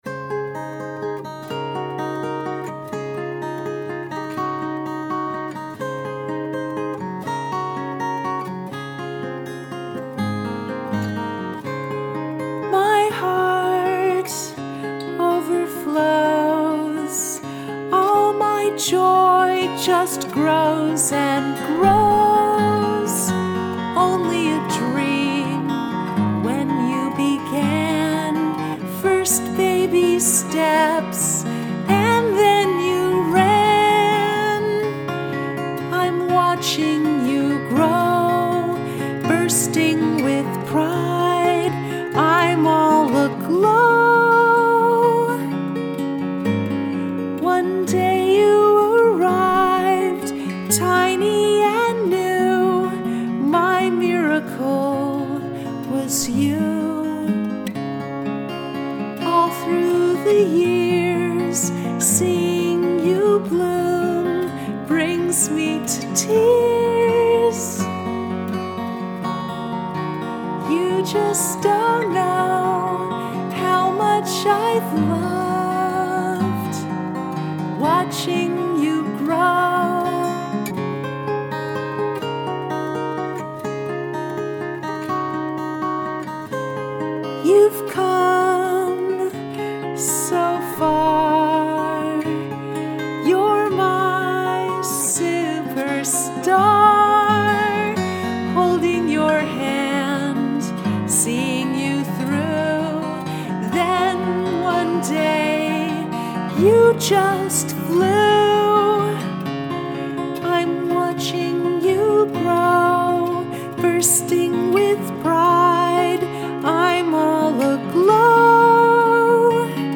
Acoustic
My song began when I heard a bouncy chord progression.
watching-you-grow-acoustic-5-2-16-mix-5.mp3